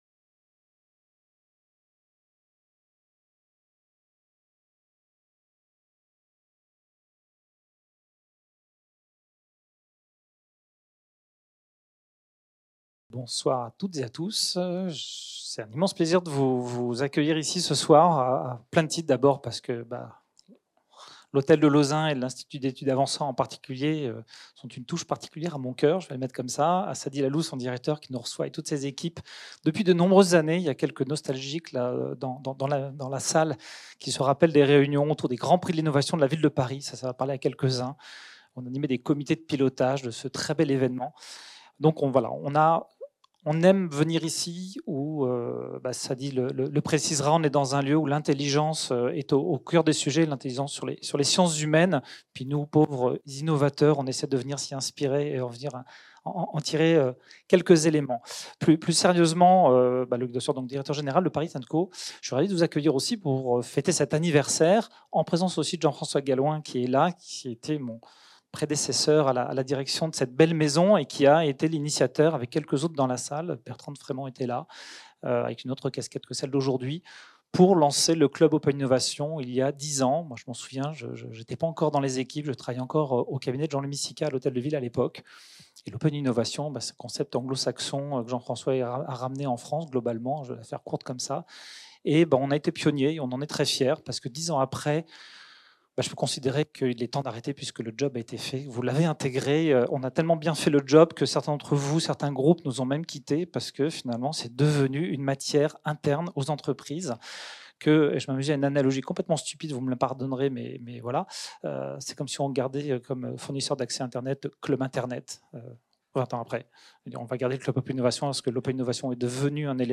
Conférence à l'occasion des 10 ans du Club Open Innovation de Paris and Co